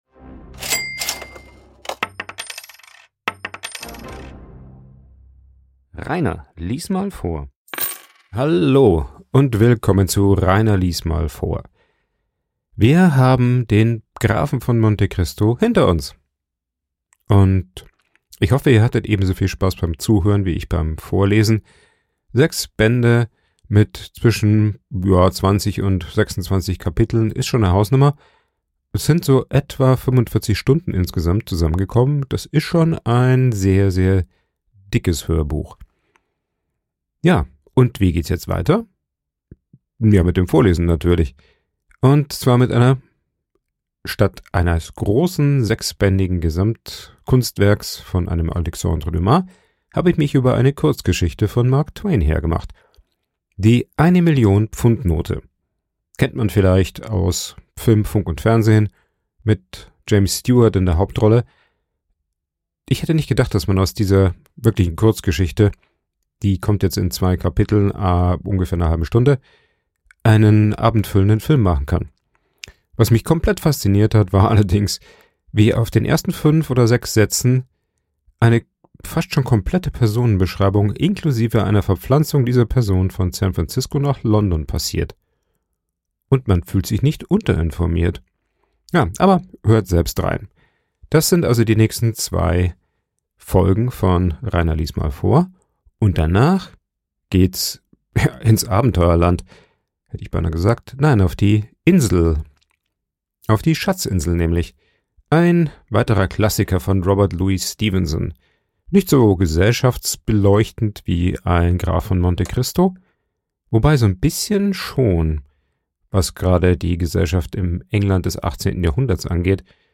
Der Vorlese Podcast
Ein Vorlese Podcast